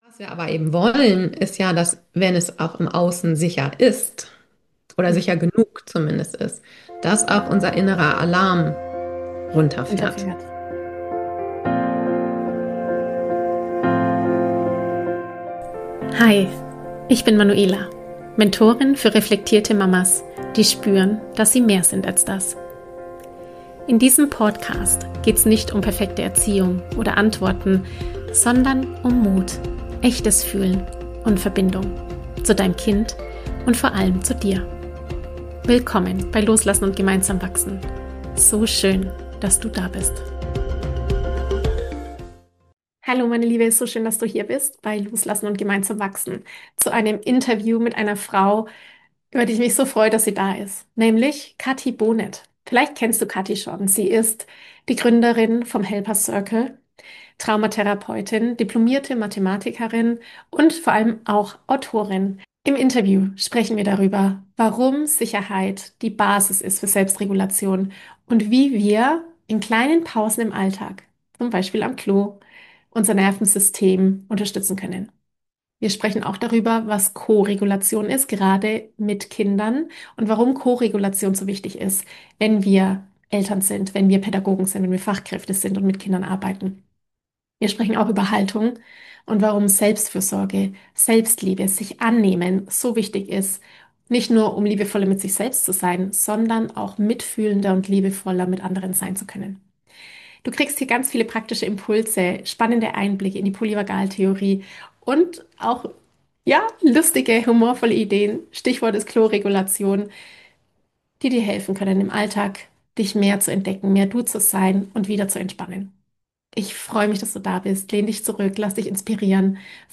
Nervensystem regulieren: Sicherheit finden für dich und dein Kind – Interview